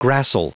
Prononciation du mot gracile en anglais (fichier audio)
Prononciation du mot : gracile